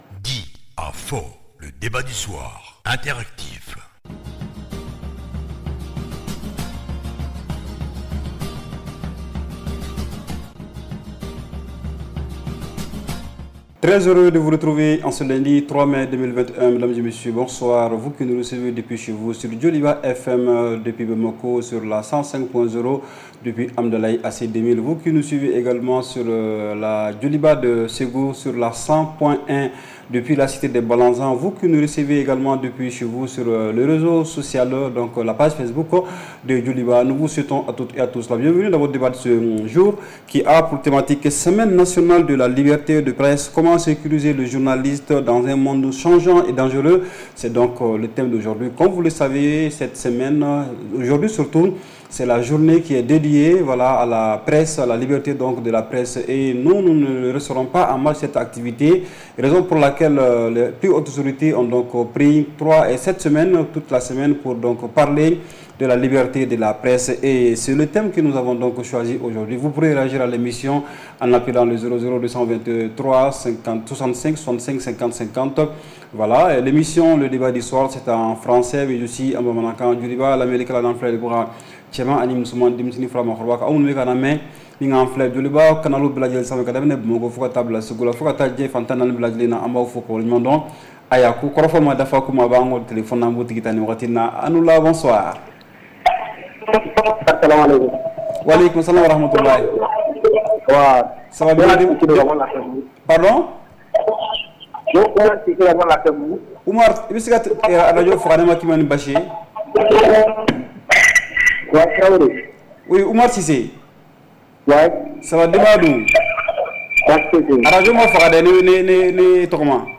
REPLAY 03/05 – « DIS ! » Le Débat Interactif du Soir